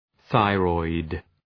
Προφορά
{‘ɵaırɔıd}